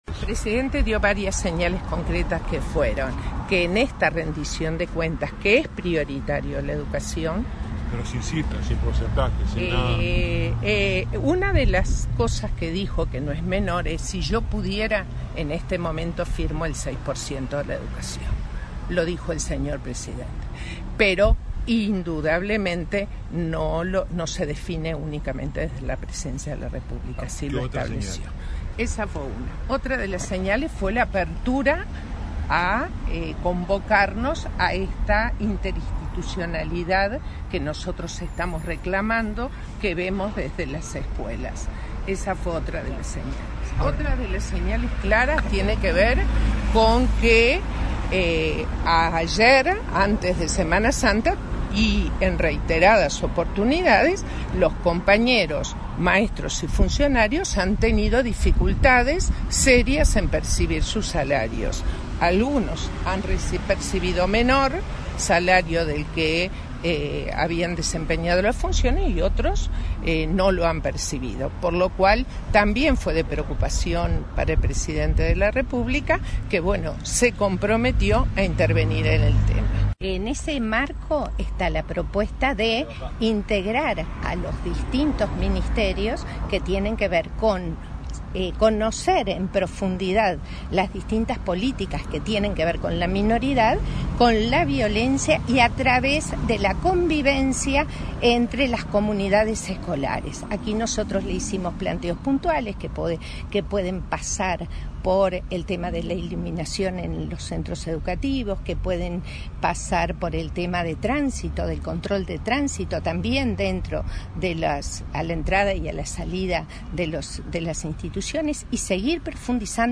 dijo en rueda de prensa